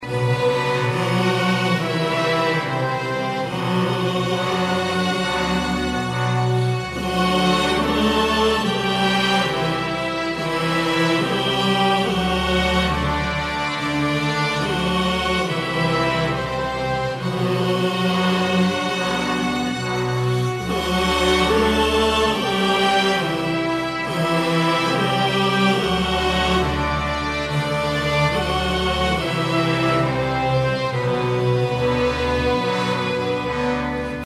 حماسی و محزون